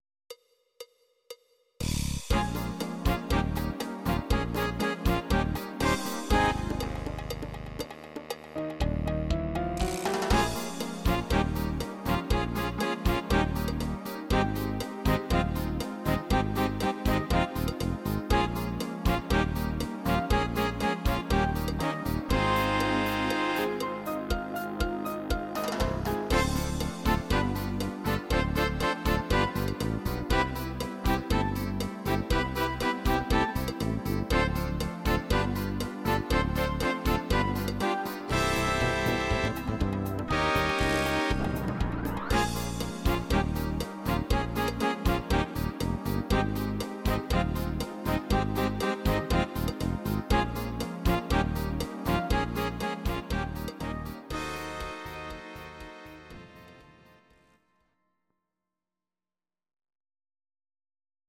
These are MP3 versions of our MIDI file catalogue.
Please note: no vocals and no karaoke included.
(instr.)